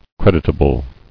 [cred·it·a·ble]